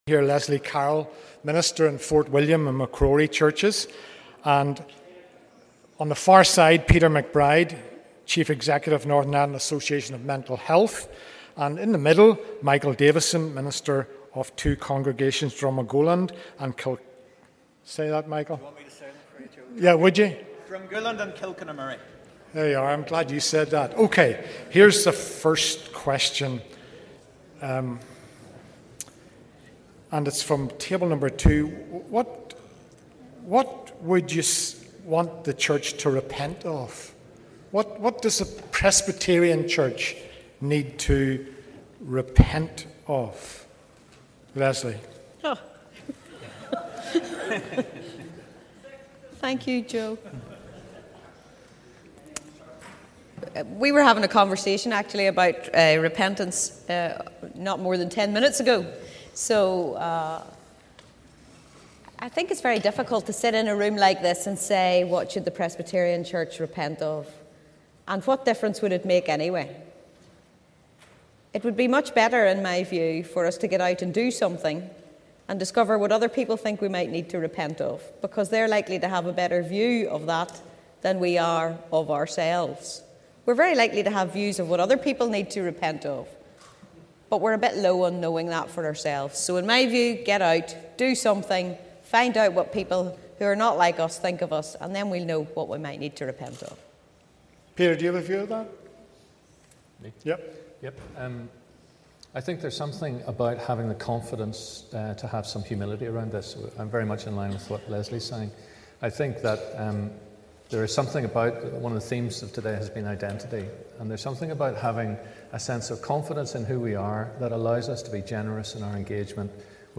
Good Relations Conference 2014 - Panel Discussion
On Saturday, 5th April Presbyterians gathered in Assembly Buildings for a morning conference entitled 'Dealing with the Past, Shaping the Future'. The panel discussion elaborated on issues raised from the day.